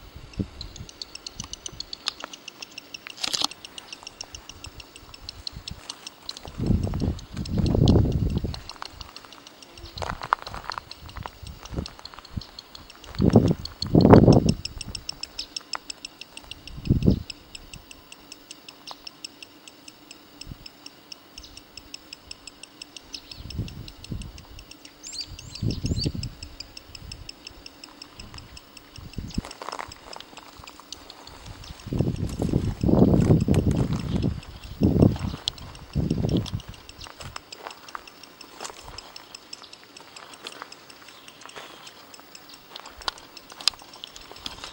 Streak-fronted Thornbird (Phacellodomus striaticeps)
Location or protected area: Tafí del Valle
Condition: Wild
Certainty: Recorded vocal
2espinero-andino.mp3